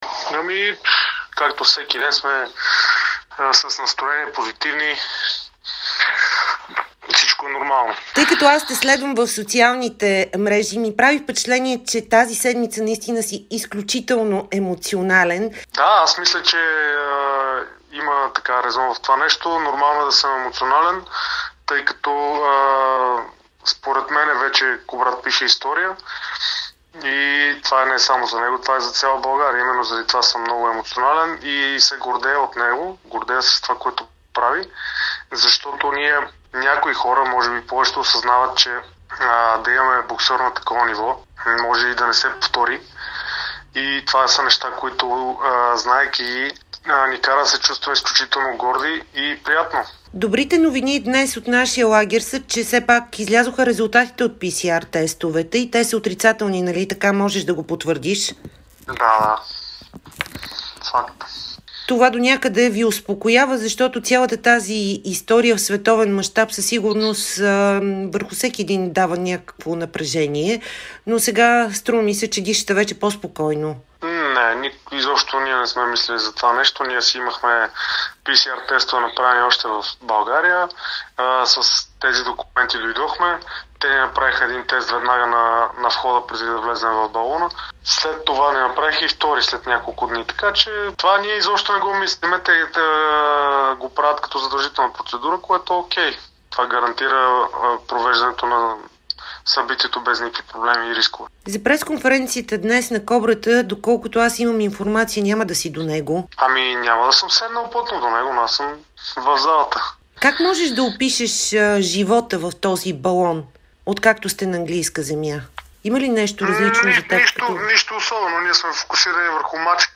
Братът на Кубрат Пулев - Тервел даде интервю за Дарик радио и dsport специално от Лондон, където в събота срещу неделя Кобрата излиза срещу Антъни Джошуа. Тервел Пулев разказа какво се случва в лагера на българина, както и каква е обстановката в балона.